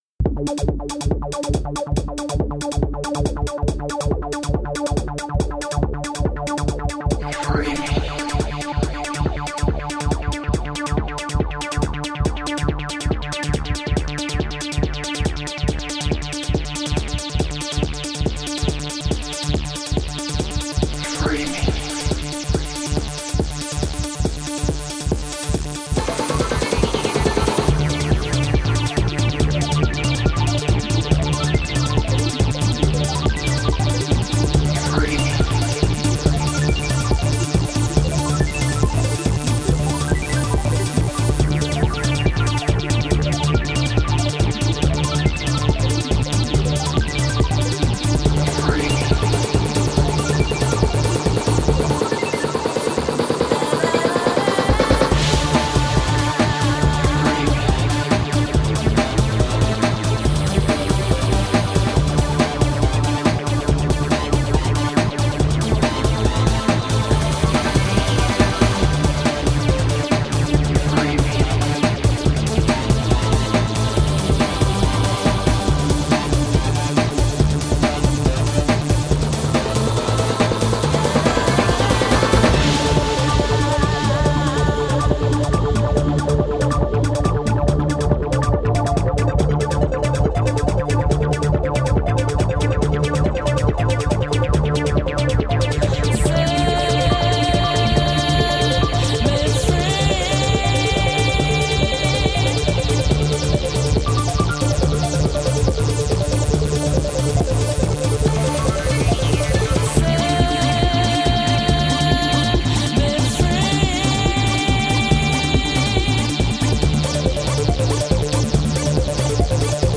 Entrancing world pop.
Tagged as: Electro Rock, Pop, Ethereal